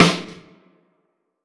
Snares
LIVEO_SNR_1.wav